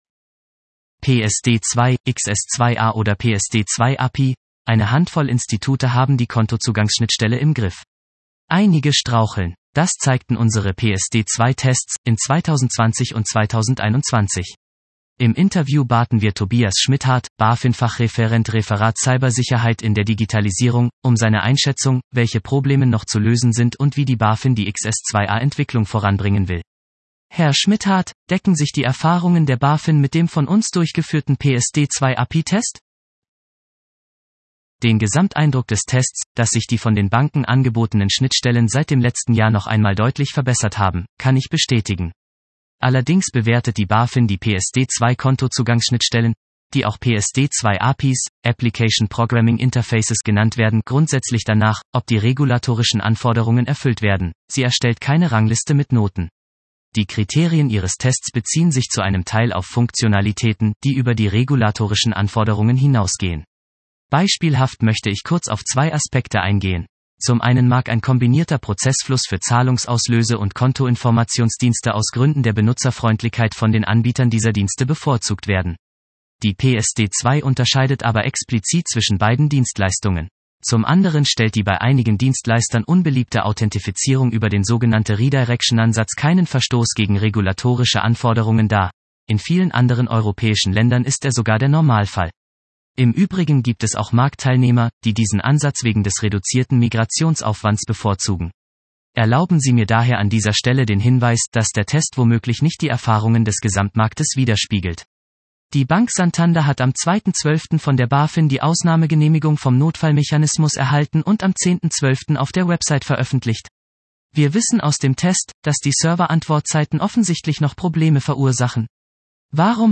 Das BaFin-Interview klärt Grenzfälle.